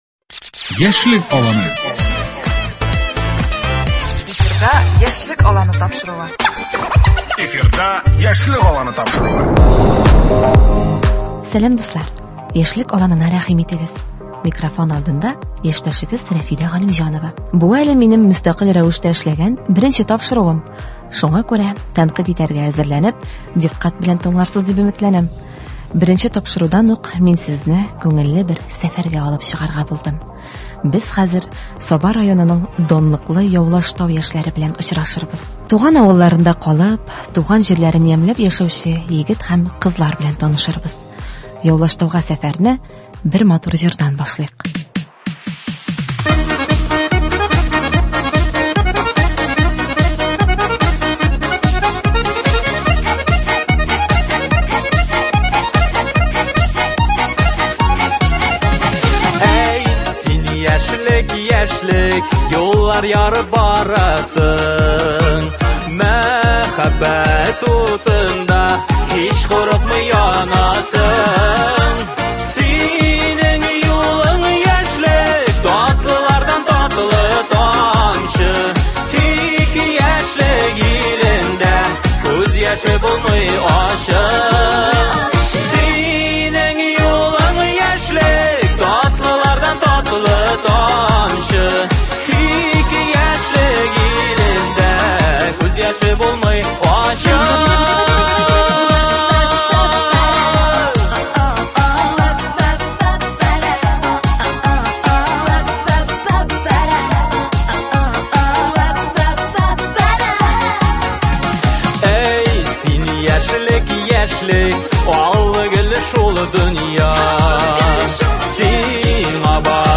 Репетицияләрен без дә карап кайттык һәм сезгә иң кызык өлешләрен тәкъдим итәргә булдык.